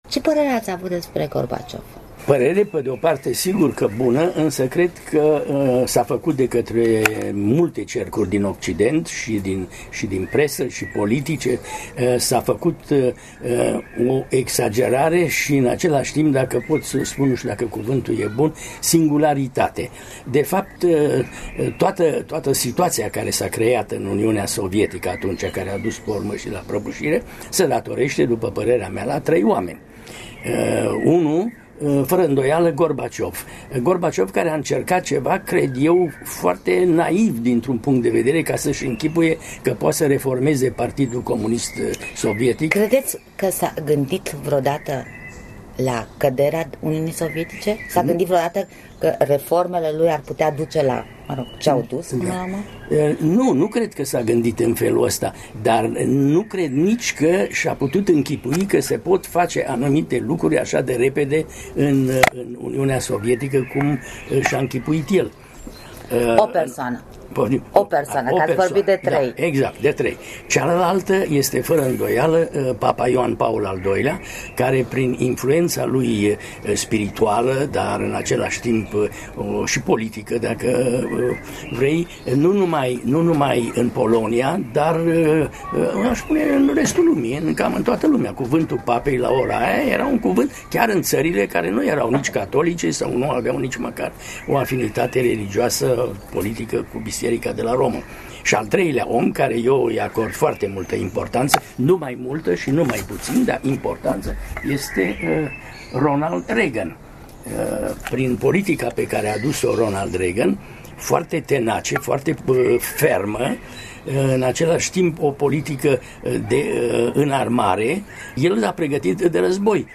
Un interviu cu Mircea Carp despre personalitatea lui Gorbaciov și perioada 1989/91